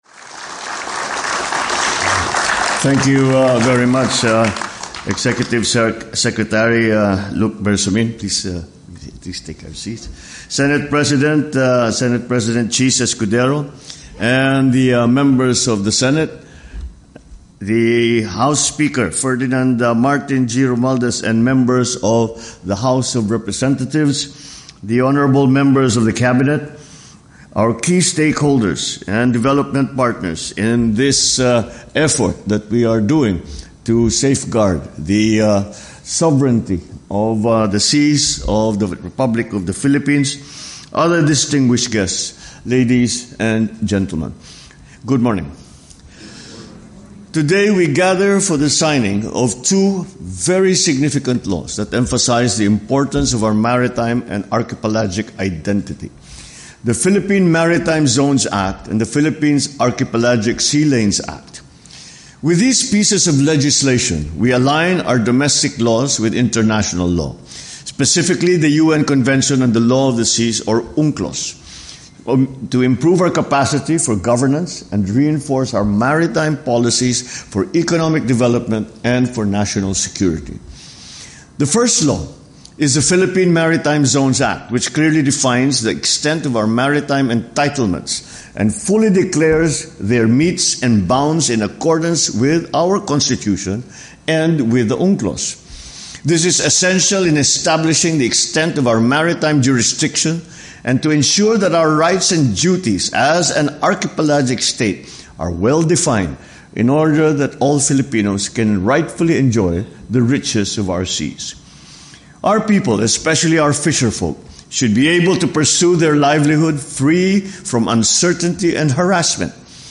Remarks at the Ceremonial Signing of the Philippine Maritime Zones and Sea Lanes Acts
delivered 8 November 2024, Ceremonial Hall, Malacañan Palace, Republic of the Philippines